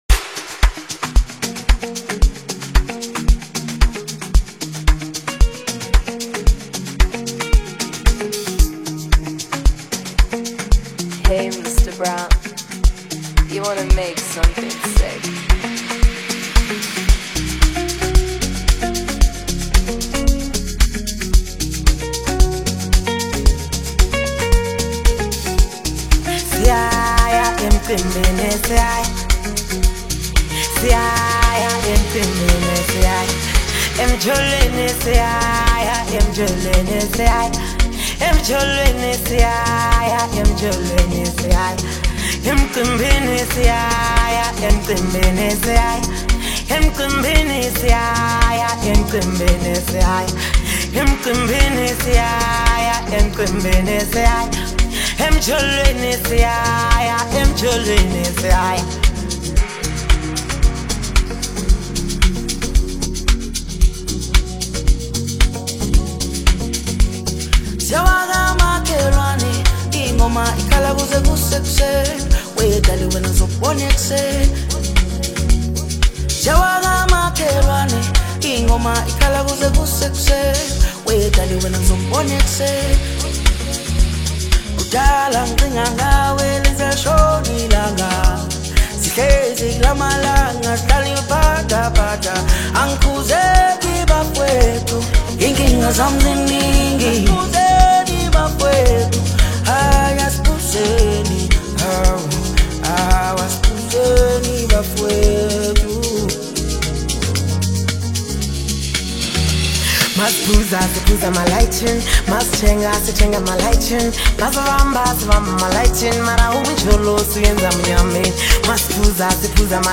DANCE Apr 07, 2026